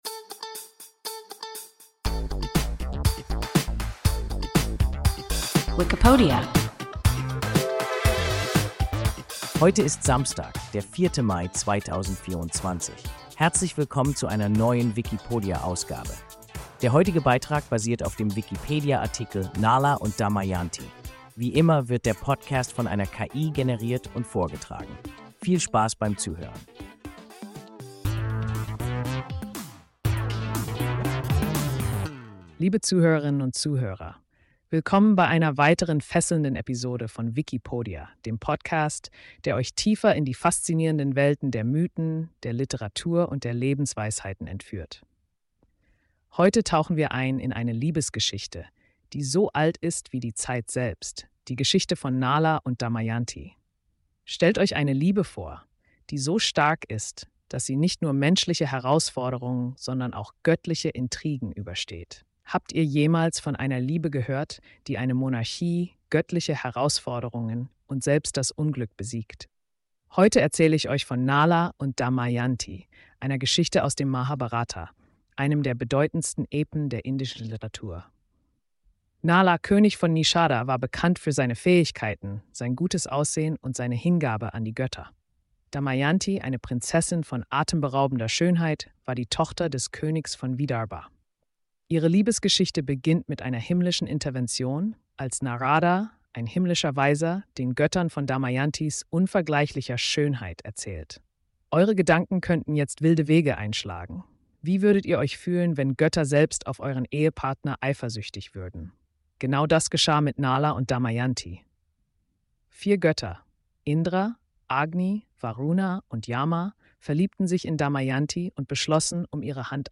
Nala und Damayanti – WIKIPODIA – ein KI Podcast